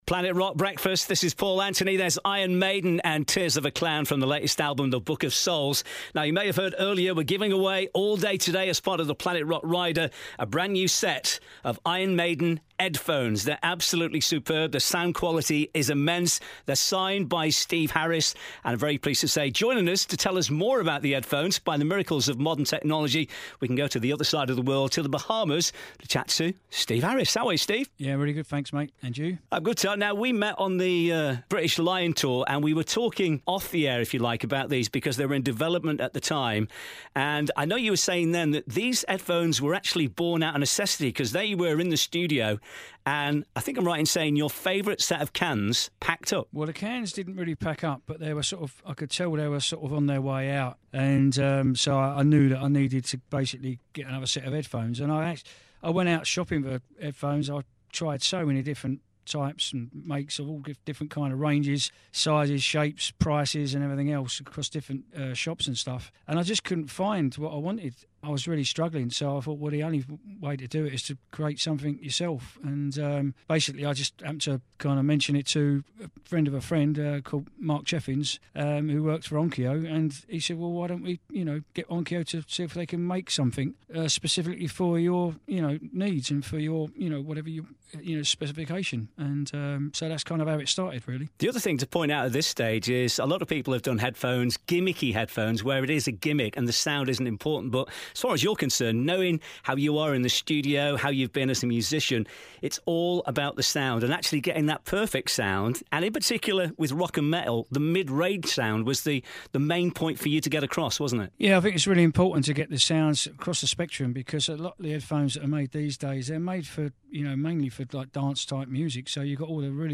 Iron Maiden's Steve Harris talks to Planet Rock about ED-PH0N3S